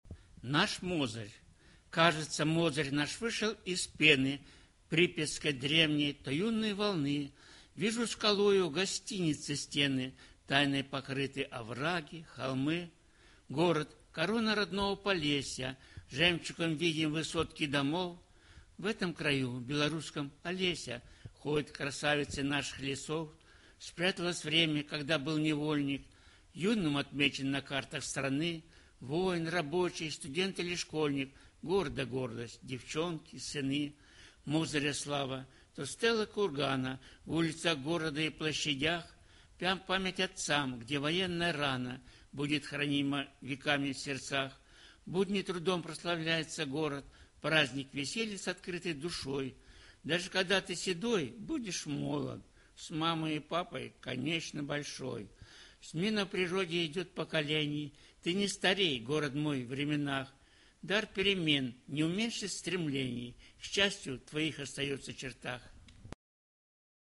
Усіх, хто любіць свой родны край і разам з тым неабыякавы да паэзіі, запрашаем паслухаць творы аб Мазыры і яго слаўных людзях. Прапануем вершы, агучаныя паэтамі, якія самі чытаюць свае творы.
Наш Мозырь (читает автор)